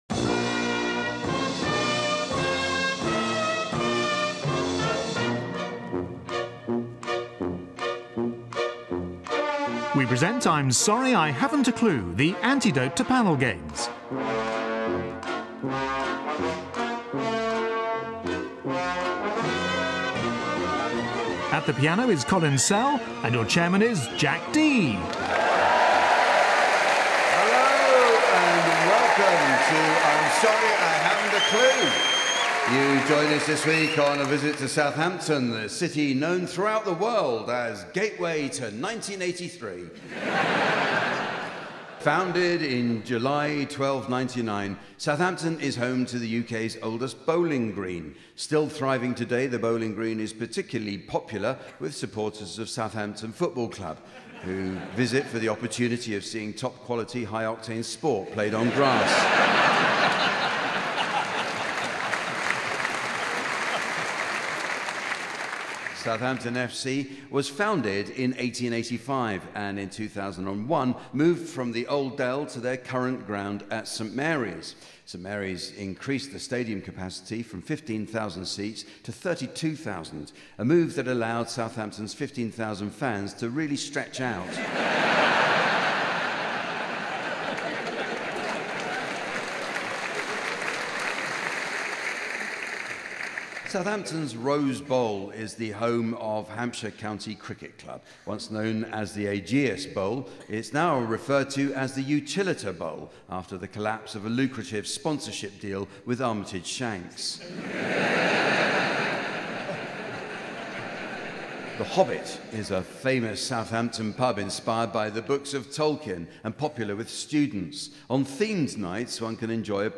The godfather of all panel shows pays a visit to the Mayflower in Southampton. On the panel are Adrian Edmondson, Rachel Parris, Miles Jupp and Marcus Brigstocke, with Jack Dee in the umpire's chair....
Regular listeners will know to expect inspired nonsense, pointless revelry and Colin Sell at the piano.